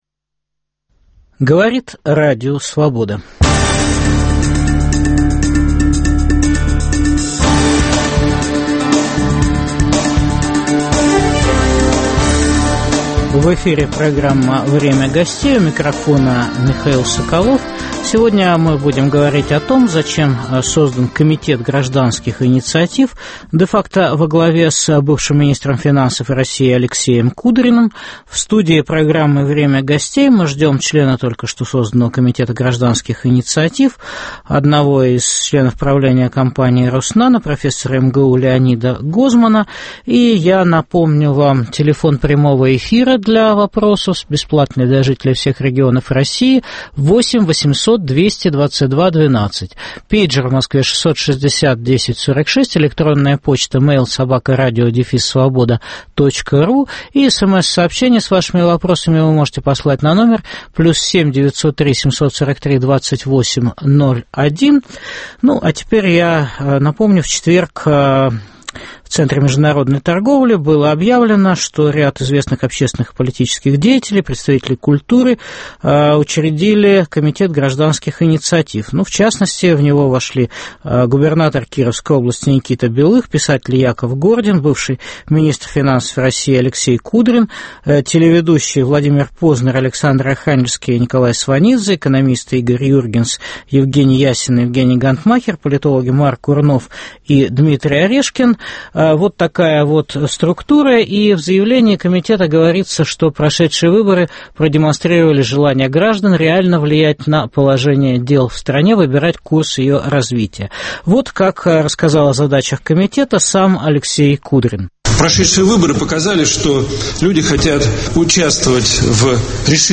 Зачем создан Комитет гражданских инициатив во главе с Алексеем Кудриным? В программе участвует член Комитета гражданских инициатив профессор Леонид Гозман.